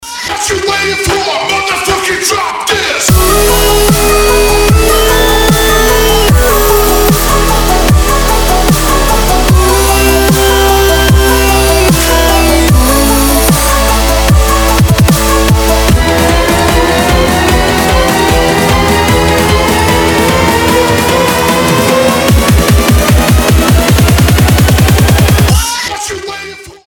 • Качество: 320, Stereo
мужской голос
громкие
мощные
Electronic
EDM
Trap
Bass
Классный громкий рингтон